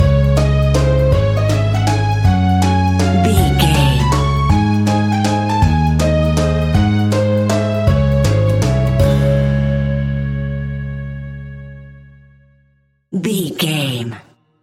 Aeolian/Minor
C#
kids instrumentals
childlike
cute
happy
kids piano